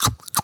comedy_bite_creature_eating_08.wav